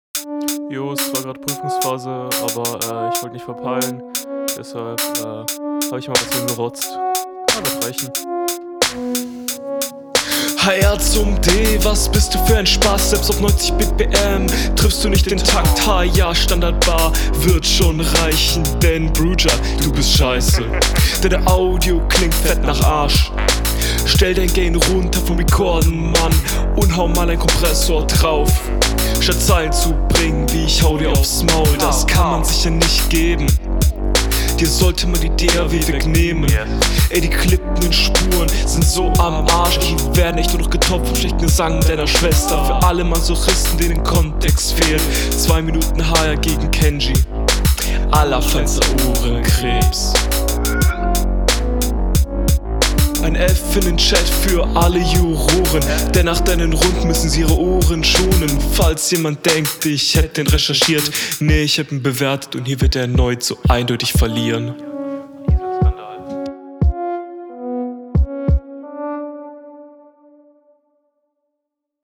Flowlich bisschen langweilig und selten Offbeat mix und Sound eigentlich gut.
Die DAW und die Schwesterline waren cool, der Flow ist aber nicht so tight mMn …